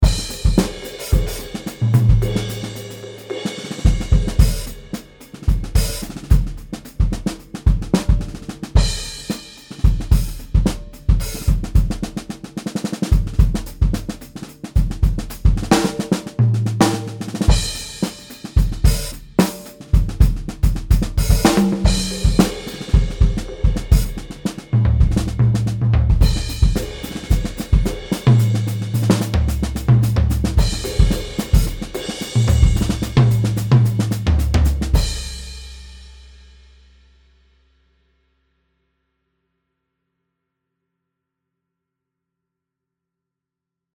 全てのドラムフレーズは同じもの（BFD3に内蔵されているもの）を使用しています。
• プリセット5：Mapleworks Mallet DVキット
独特のアタックの丸み、弱いベロシティーの時にも太鼓そのものが鳴っているような響き。グルーブが一歩後ろにずれたような金物のノリ。このアタック感を逃さないため、アンビエントマイクはトップマイク（ドラムの真上に立てているマイク）を生かすように仕上げています。 また、スネアの皮の響きを生かすため、ほんの少しスネアトップのマイクを上げています。
BFD3Preset_MapleworksMalletDV.mp3